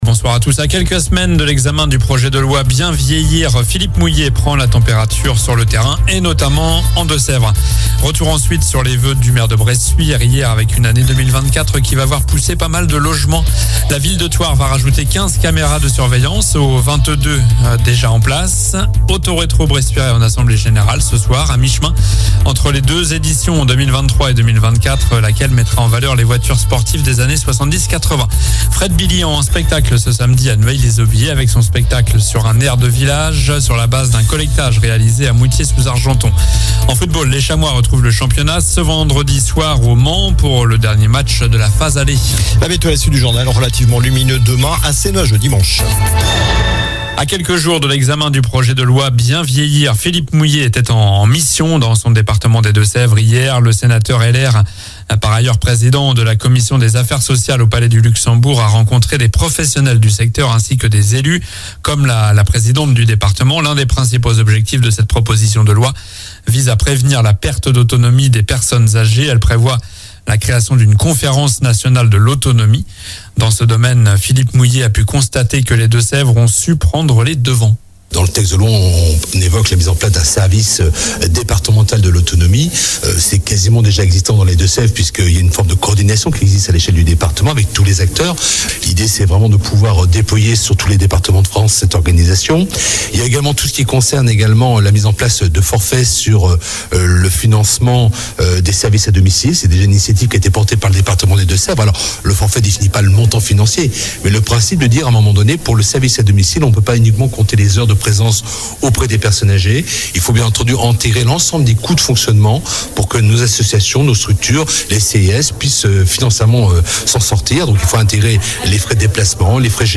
Journal du vendredi 12 janvier (soir)